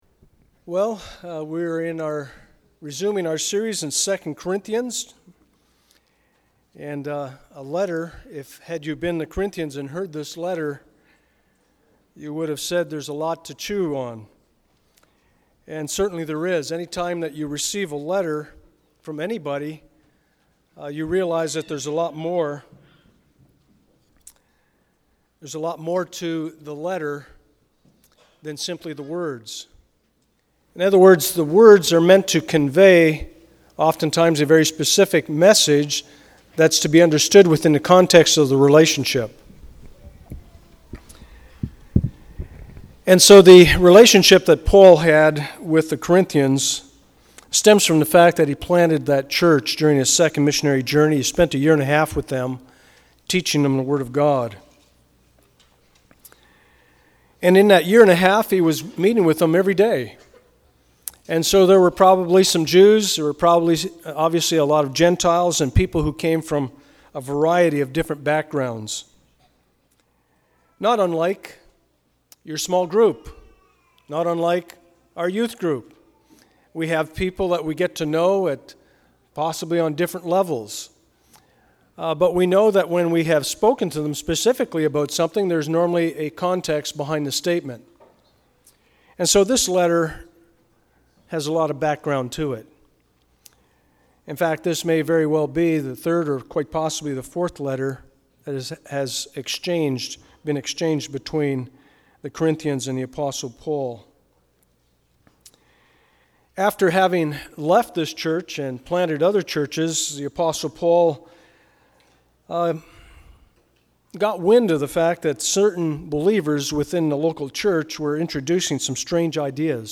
2 Corinthians Passage: 2 Corinthians 6:1-24 Service Type: Sunday Morning « What Are You Thankful For?